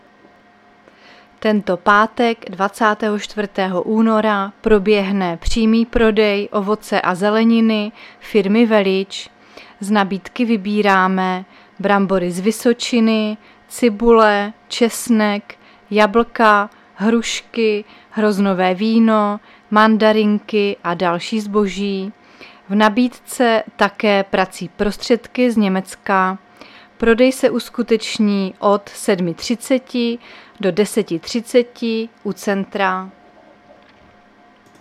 Záznam hlášení místního rozhlasu 22.2.2023